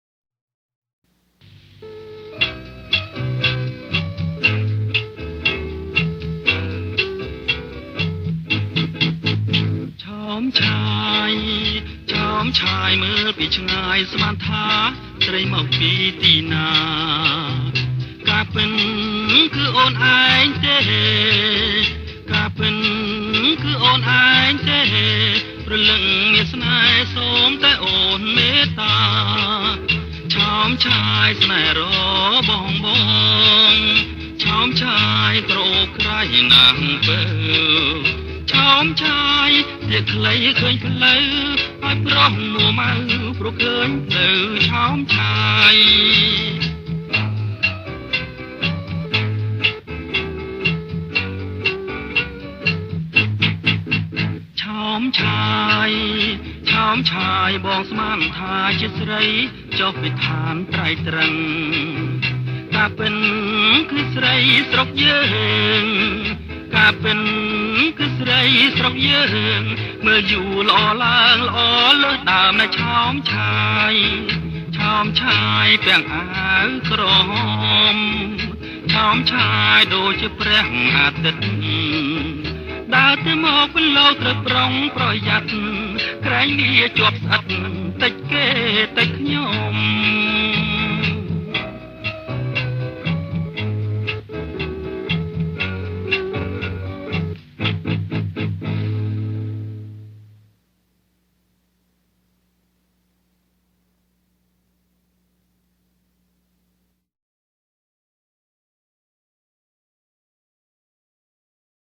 • ប្រគំជាចង្វាក់ CHA CHA CHA